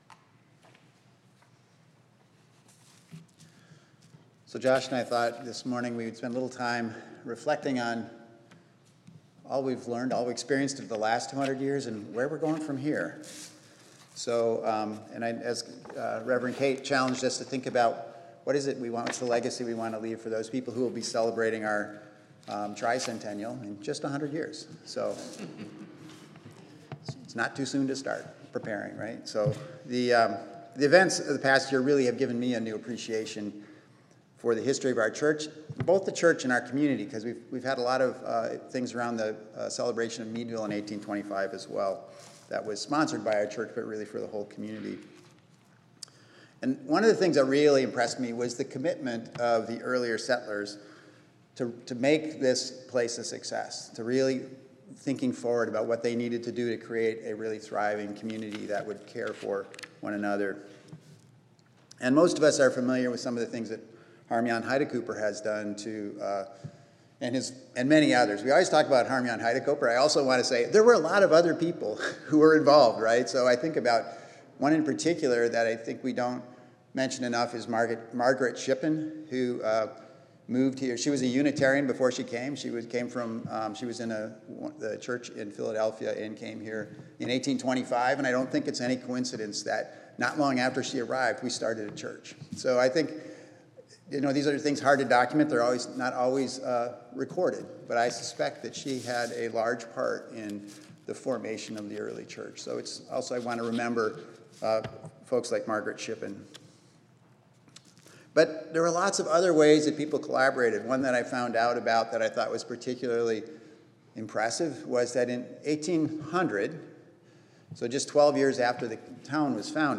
In this service, we consider the people who will come after us. What message do we want to impart to those people who will be a part of this community in 100 or even 200 years? This service will challenge us to reflect deeply on who we are and who we want to be for our community.